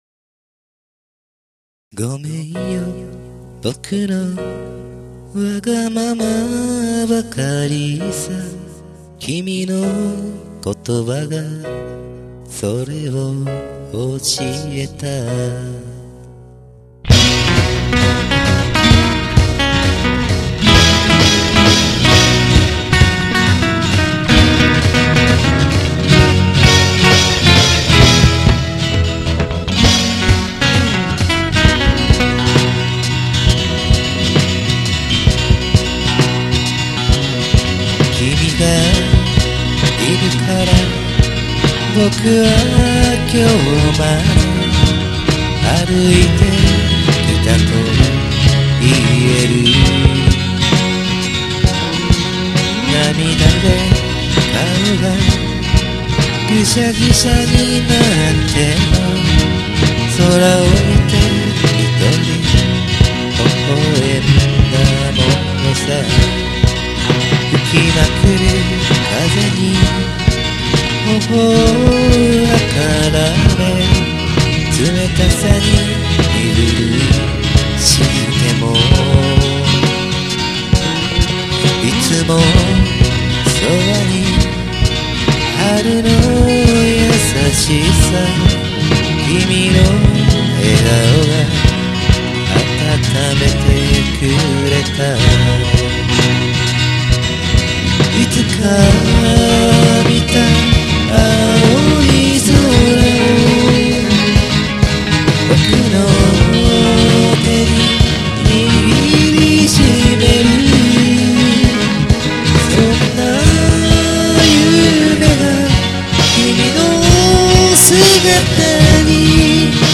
AG1  Gibson
AG2  Ovation
ドラム  Pistoncollage
今回もドラムスとキーボードはＰＣ音源なんですけど
やっぱ小節毎区切りで打ち込みするんっすわ。